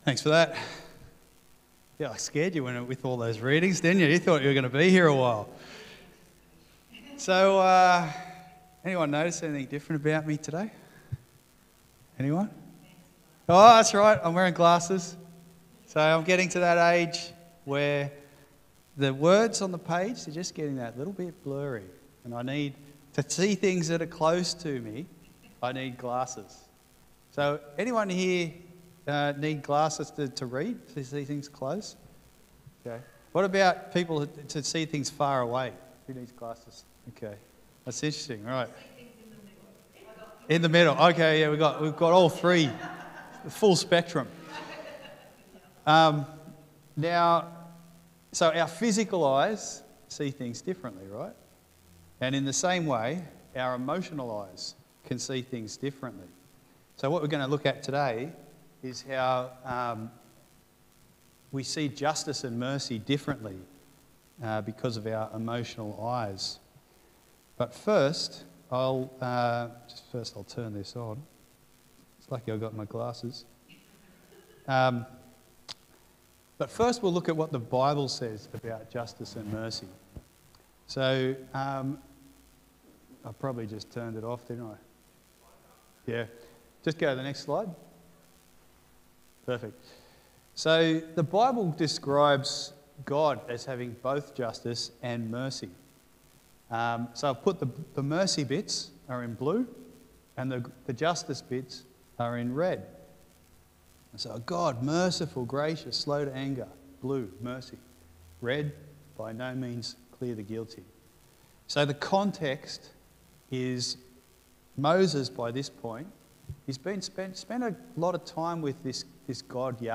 but Love Mercy Preacher